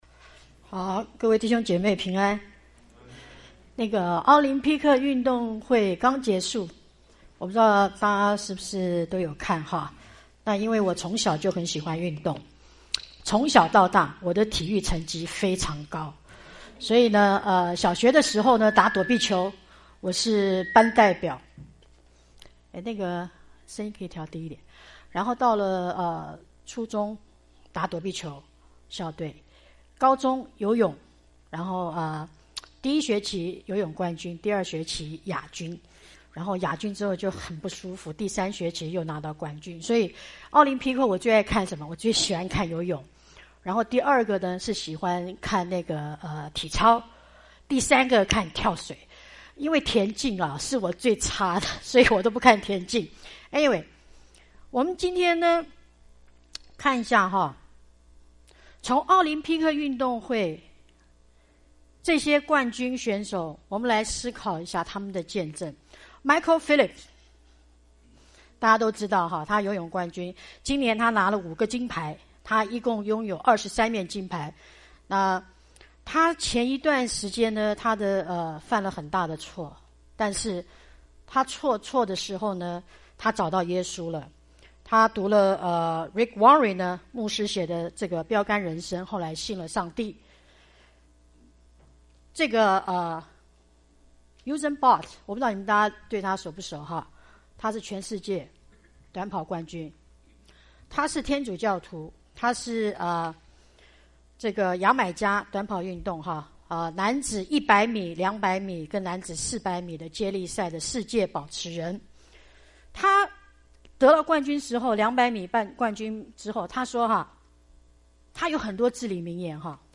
傳道 應用經文: 哥林多前書9:19-27